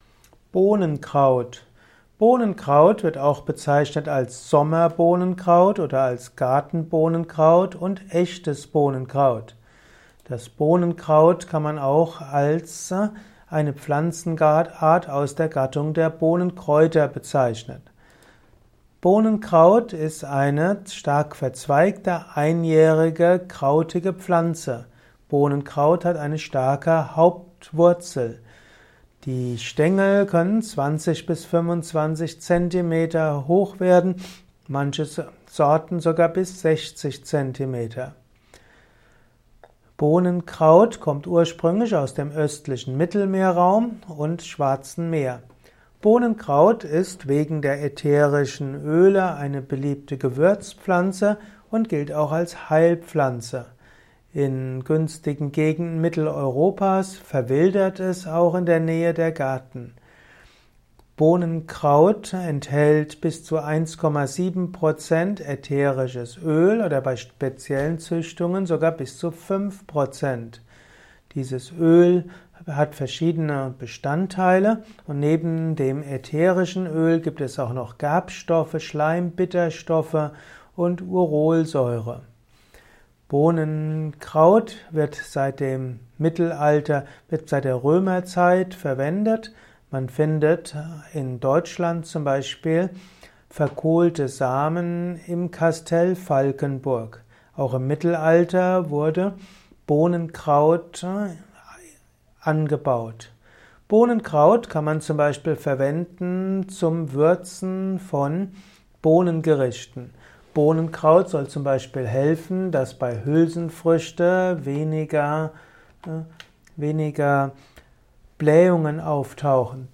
Kompakte Informationen zum Bohnenkraut in diesem Kurzvortrag